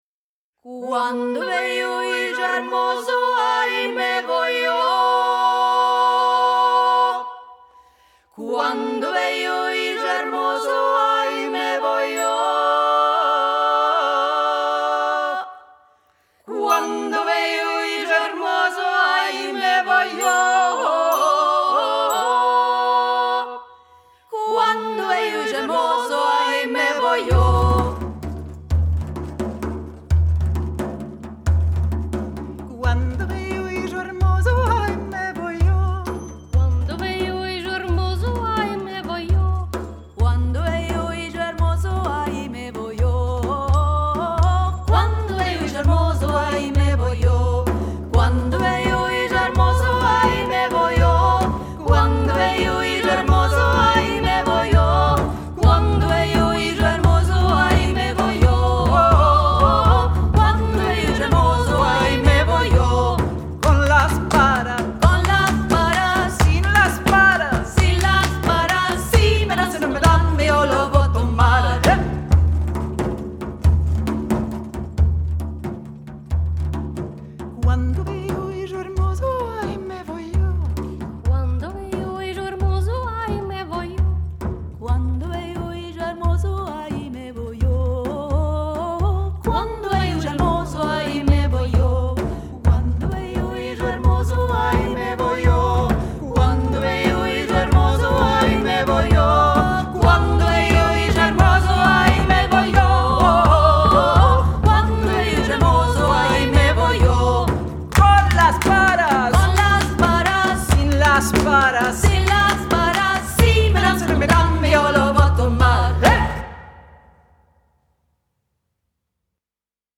Our Sephardic programme Las tres ermanikas: